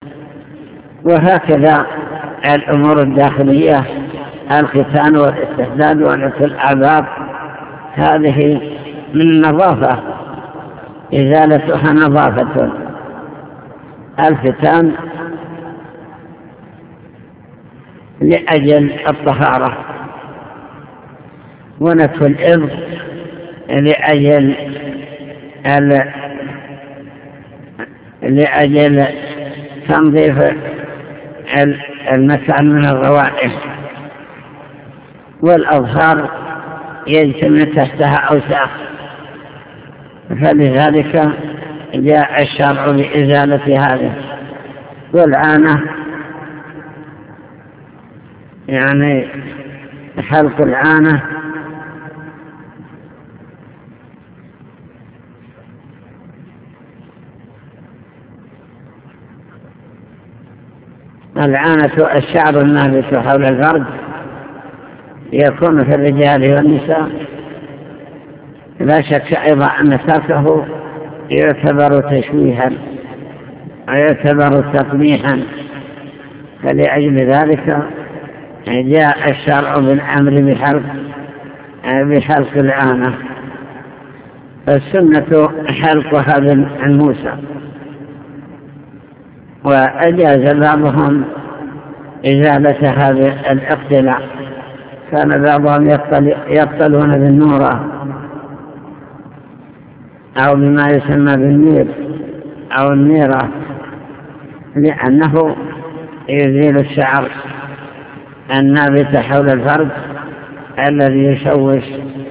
المكتبة الصوتية  تسجيلات - كتب  شرح كتاب دليل الطالب لنيل المطالب كتاب الطهارة سنن الفطرة